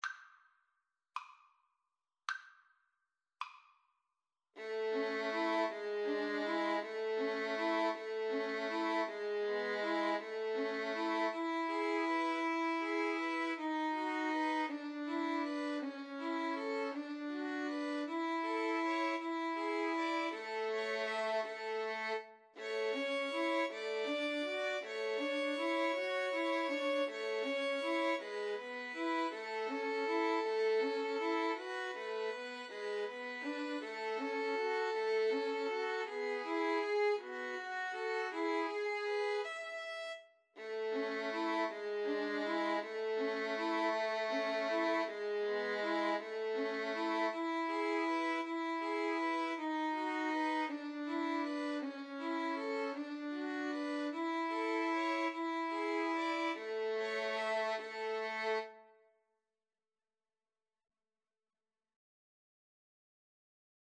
6/8 (View more 6/8 Music)
Andante
Pop (View more Pop Violin Trio Music)